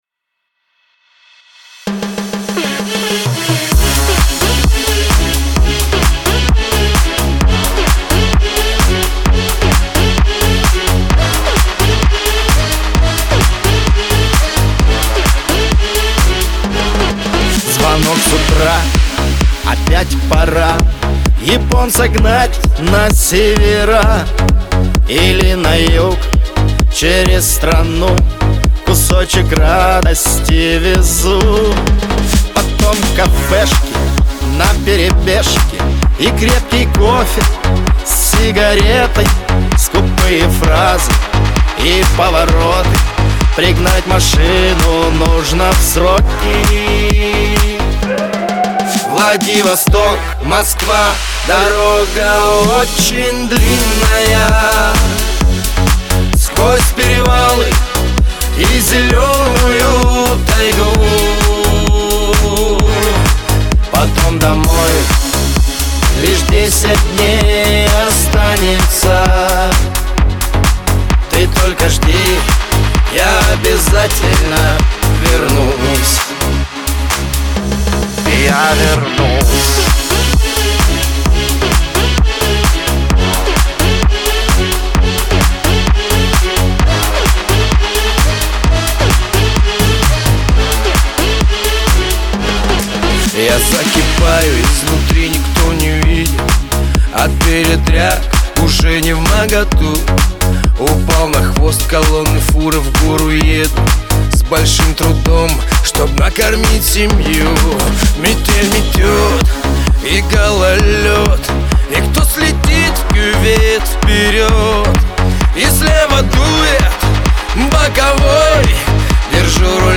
дуэт
Лирика , Шансон